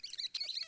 rat1.wav